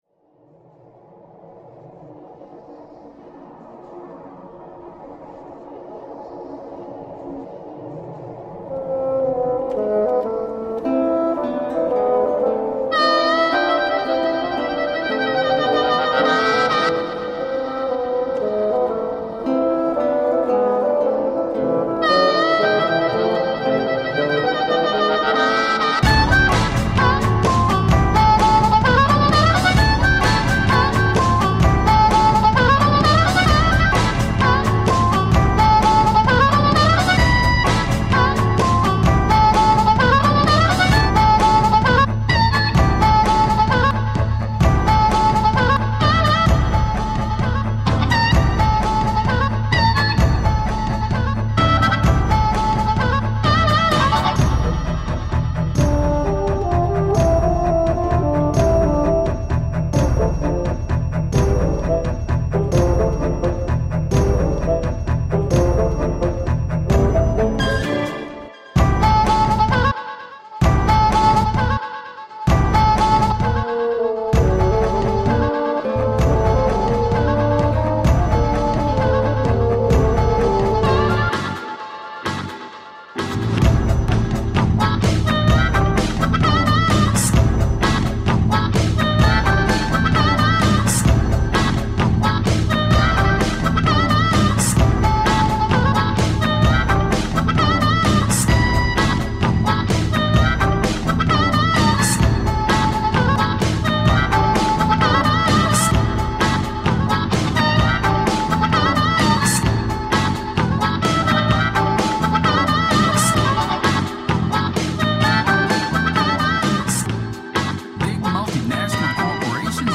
Electronica, Rock, Alt Rock, Remix